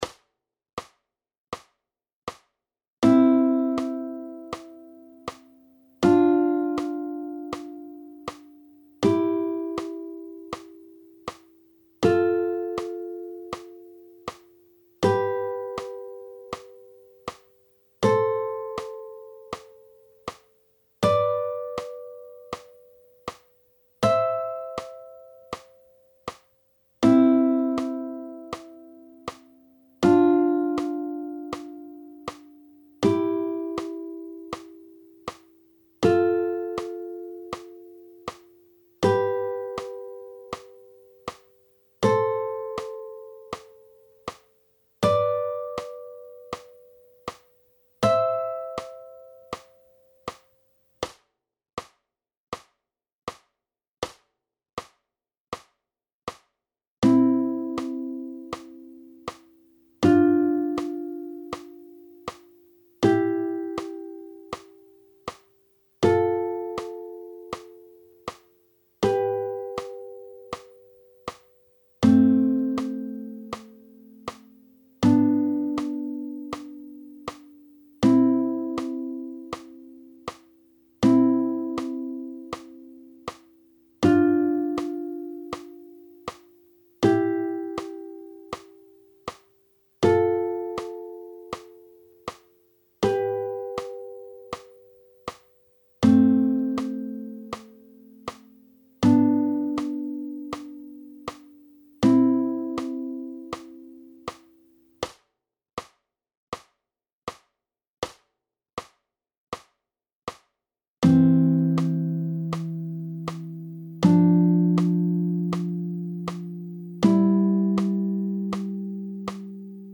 !!! C-Dur-TL in Terzen und Sexten (Komplementärintervalle) auf Saite 1 – 4: PDF
C-Dur-Tonleiter-mit-Terzen-und-Sexten-Saite-1-4.mp3